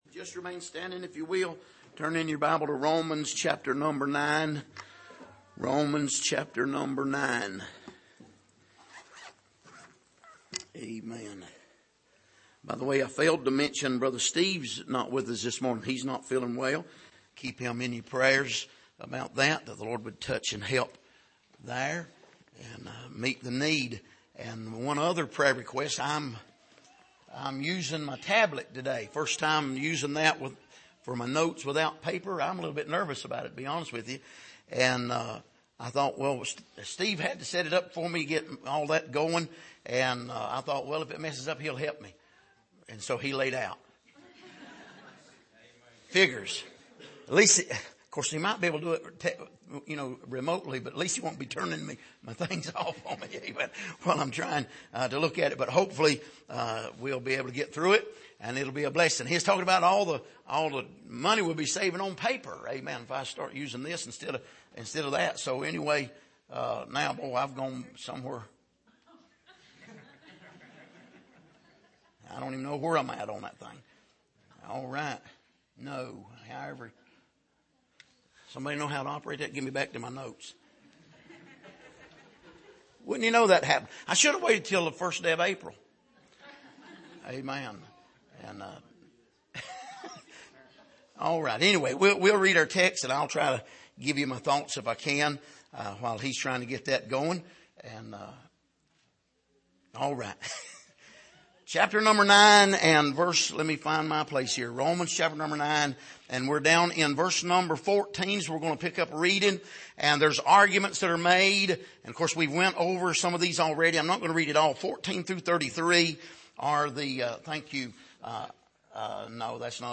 Passage: Romans 9:14-24 Service: Sunday Morning